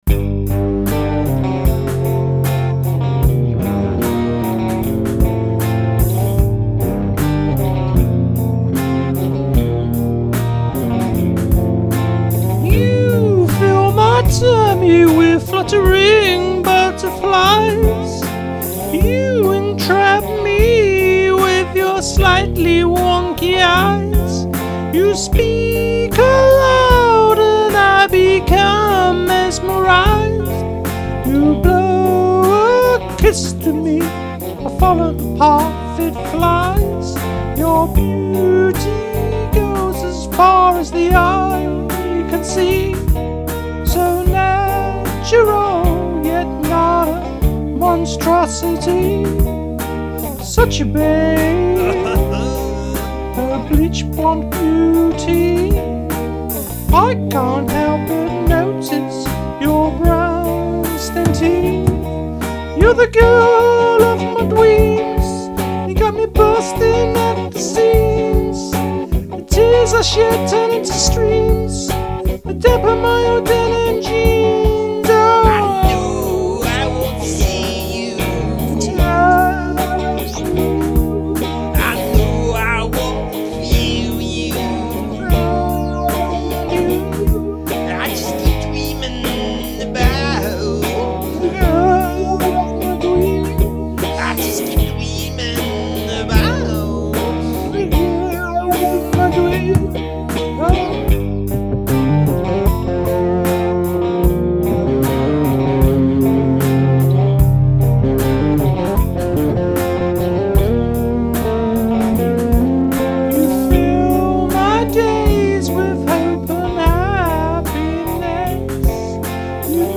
what a falsetto!